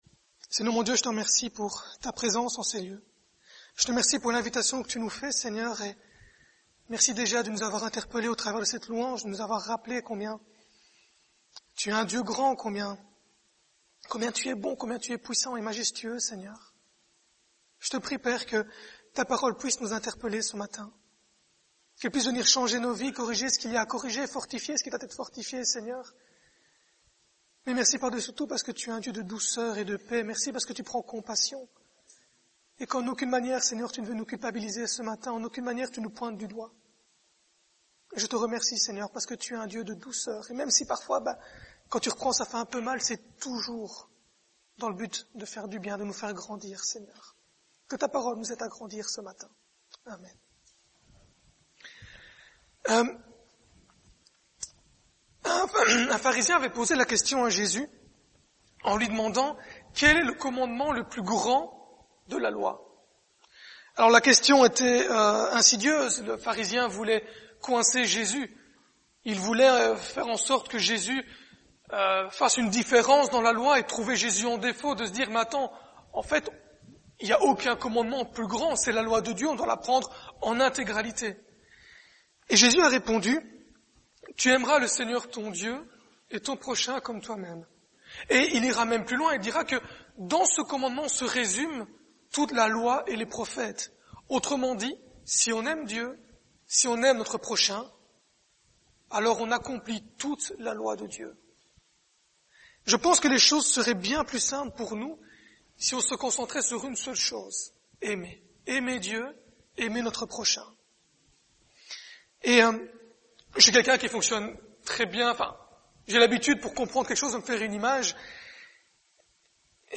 Passage: Galates 5 : 25-26 - 6 : 1-5 Type De Service: Dimanche matin